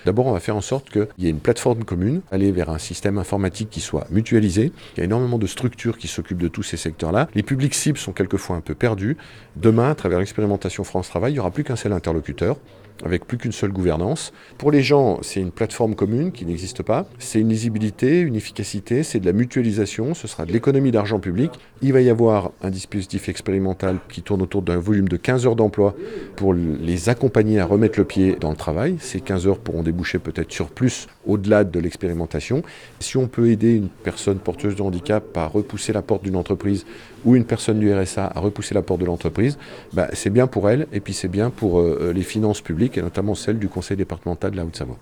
Martial Saddier explique comment le dispositif va s’organiser.